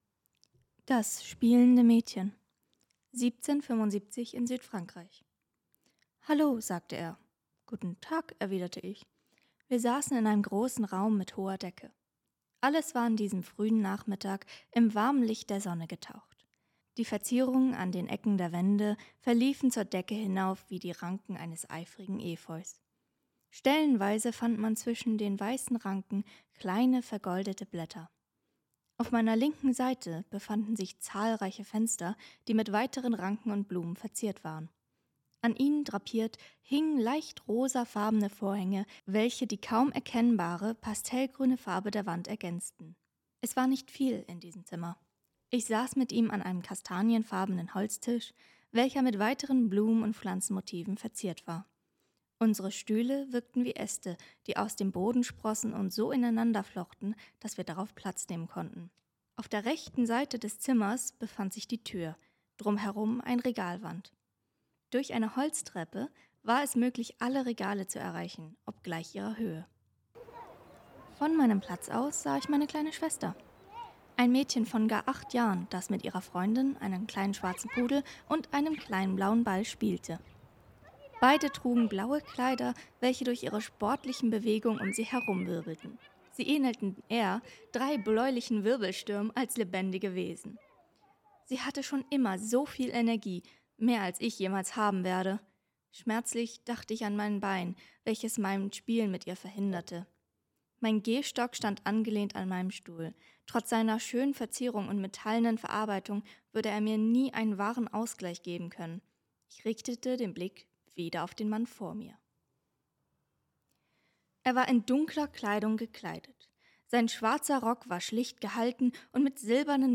Die Geschichte ist hier auch als Audio verfügbar.